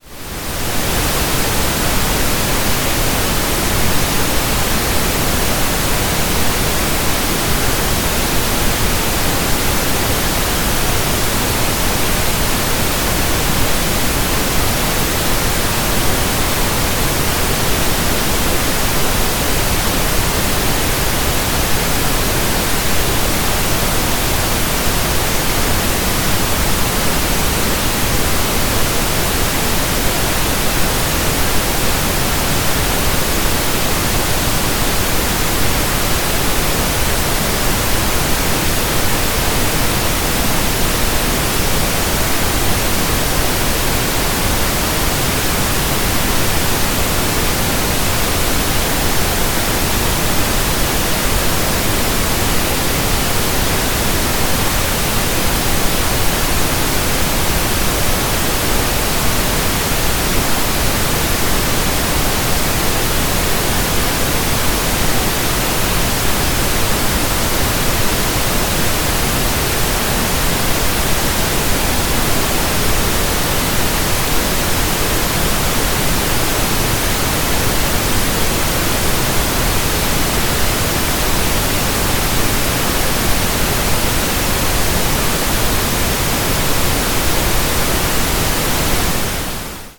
Télécharger le protocole, les fiches de mesure et le fichier de bruit rose
BruitRose90s_QualitéStd
BruitRose90s_QualiteStd.mp3